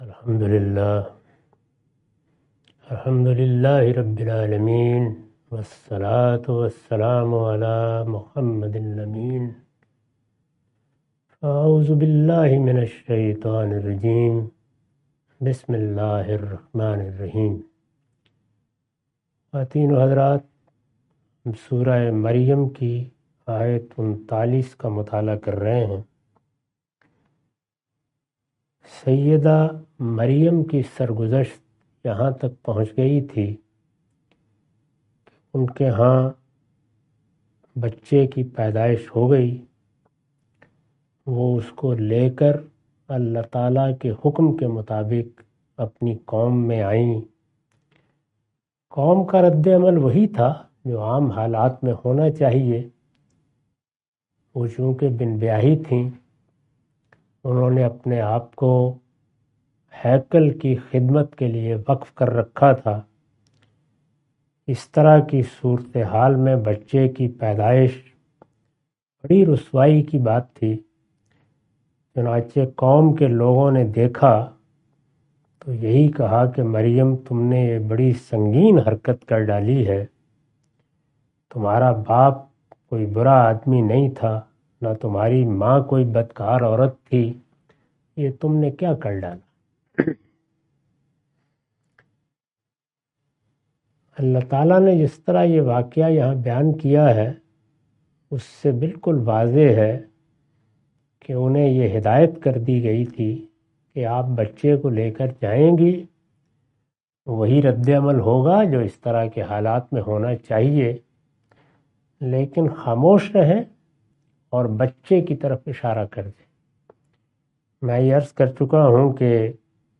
Surah Maryam A lecture of Tafseer-ul-Quran – Al-Bayan by Javed Ahmad Ghamidi. Commentary and explanation of verses 29-33.